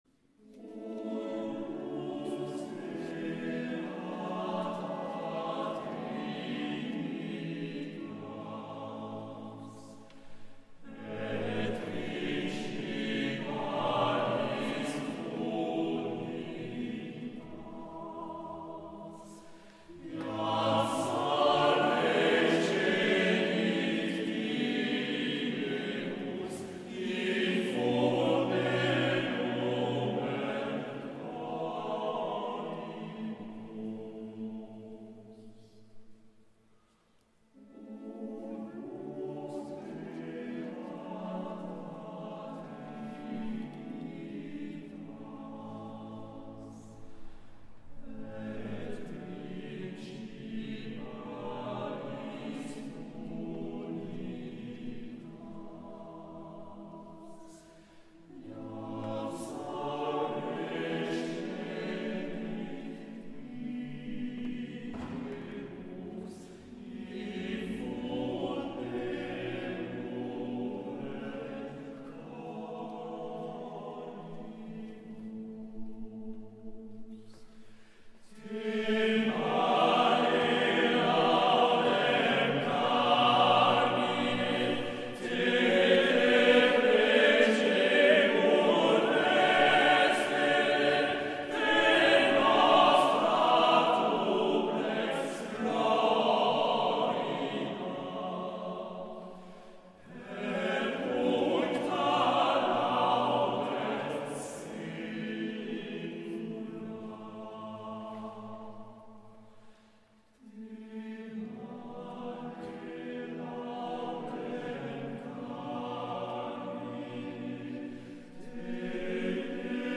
Offertoire Ô Lux beata Trinitas Feuillet
Lieu Eglise Saint-François-de-Paule (Fréjus)